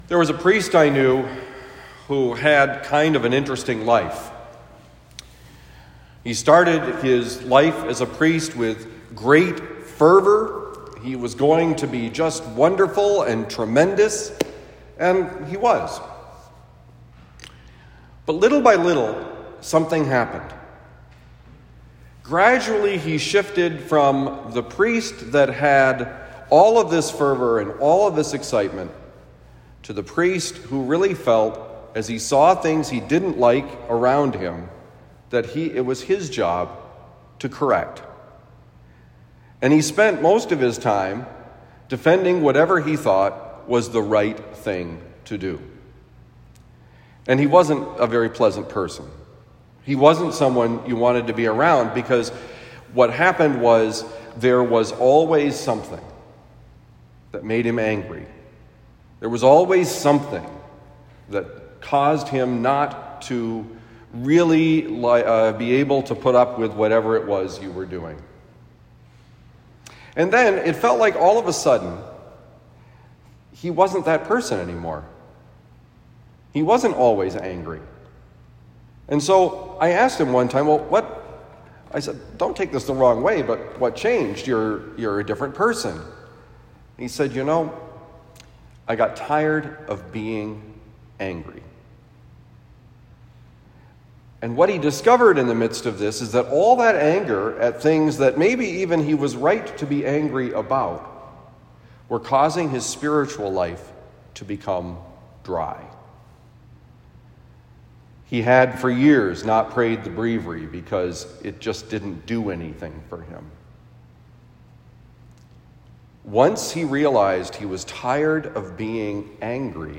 Homily given at Saint Dominic Priory, Saint Louis, Missouri.